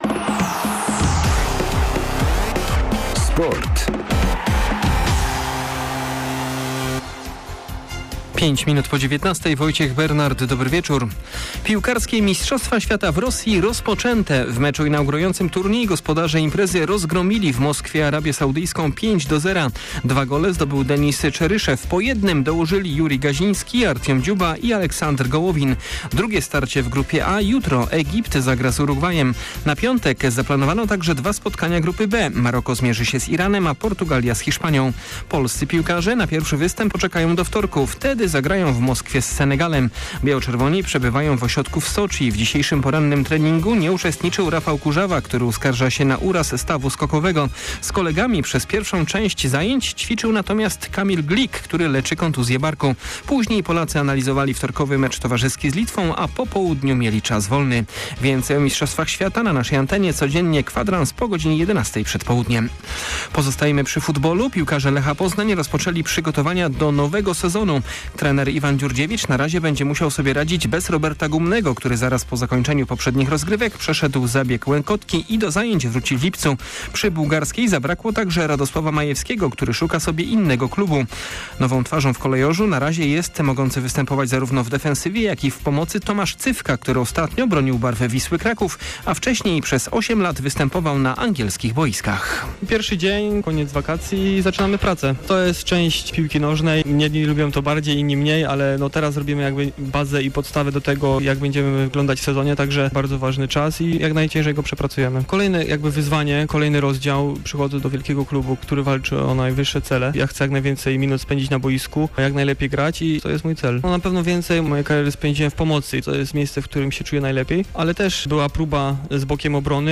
14.06 serwis sportowy godz. 19:05